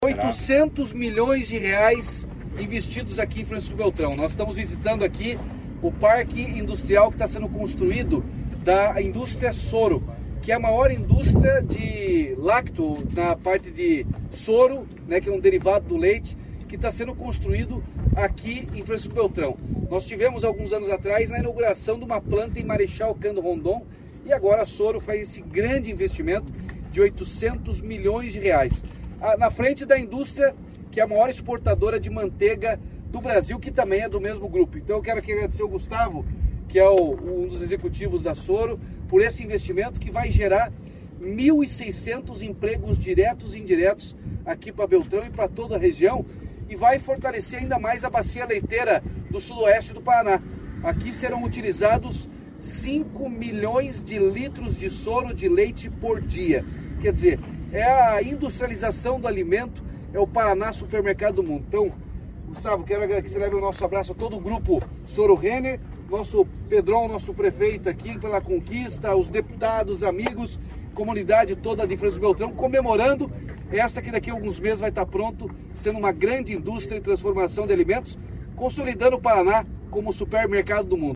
Sonora do governador Ratinho Junior sobre a visita a obra da fábrica da Sooro Renner, em Francisco Beltrão